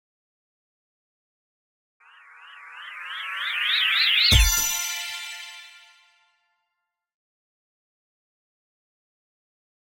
Звуки вихря, круговорота
Эффект вихря и исчезания (появления)